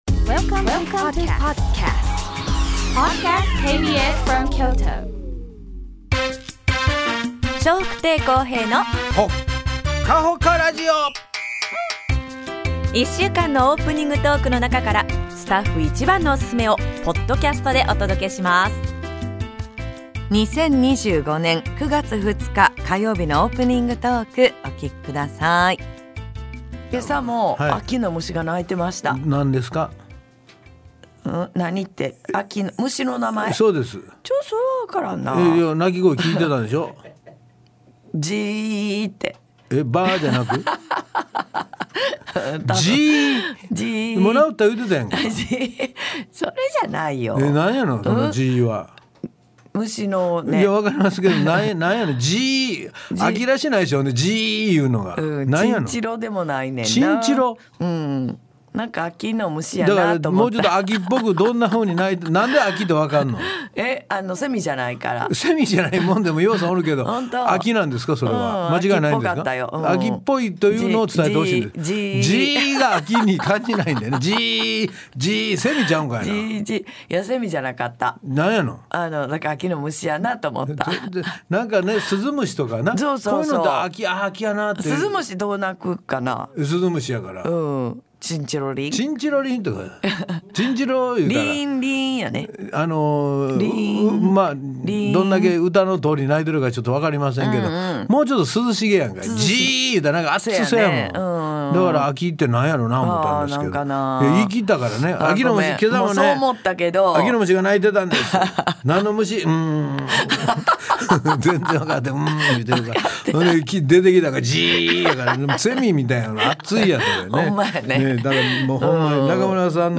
2025年9月2日のオープニングトーク